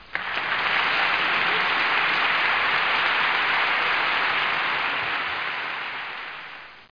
00110_Sound_APPLAUSE2
1 channel